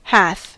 Ääntäminen
Vaihtoehtoiset kirjoitusmuodot (vanhahtava) hathe Ääntäminen US Tuntematon aksentti: IPA : /hæθ/ Haettu sana löytyi näillä lähdekielillä: englanti Käännöksiä ei löytynyt valitulle kohdekielelle.